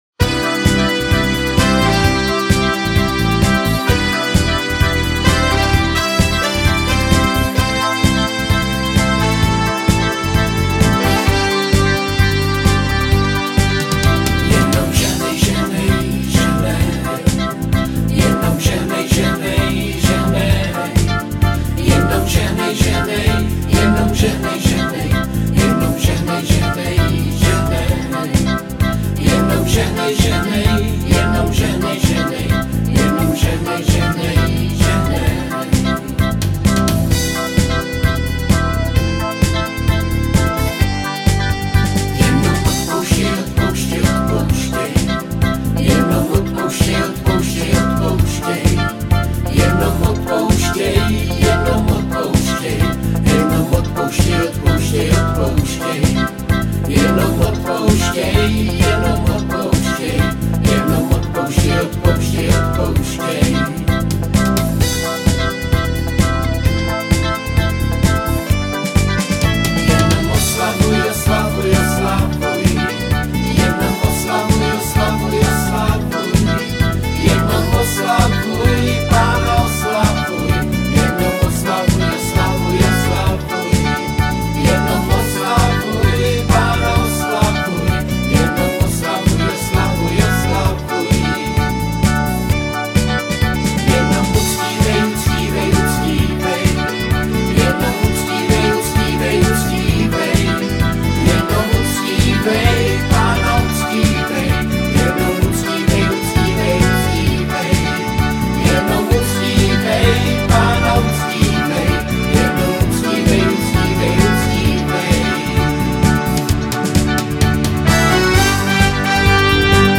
Křesťanské písně
Písně ke chvále a uctívání